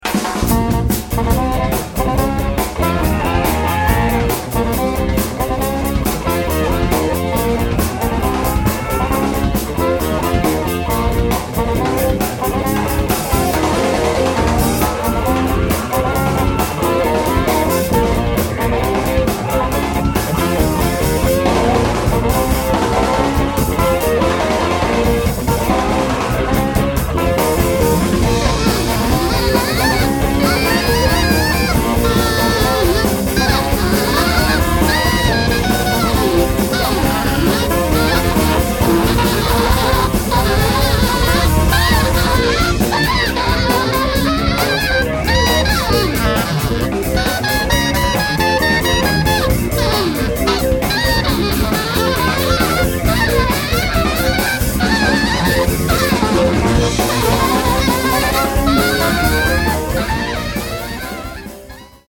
sassofono tenore e baritono, clarinetto basso
sassofono alto e soprano
chitarra
basso
batteria
registrato in Svizzera